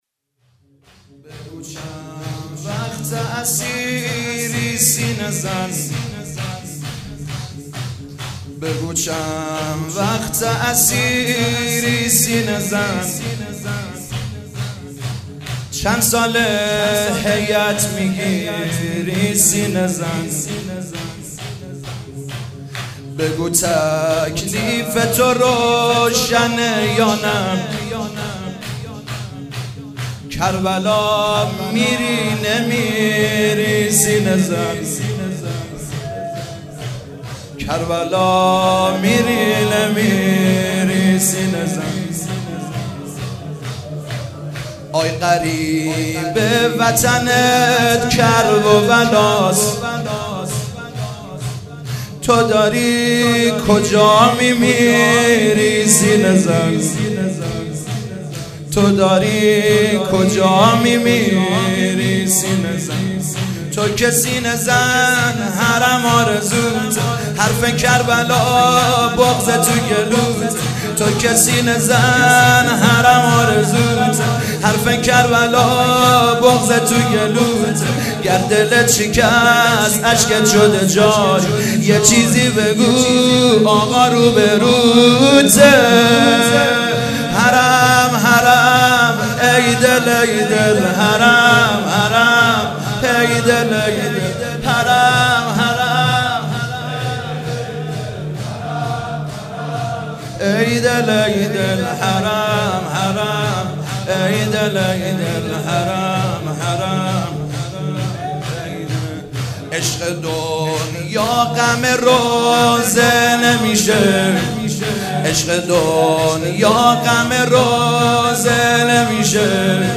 شب هفتم محرم95/هیئت مکتب الزهرا(س)
شور/بگو چند وقته اسیری